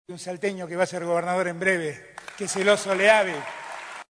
Así lo mencionó durante un acto en San Miguel de Tucumán, el dirigente nacional del Frente de Todos, Alberto Fernández.»Ahí hay un salteño que va a ser gobernador en breve, Sergio Oso Leavy» señaló en su discurso.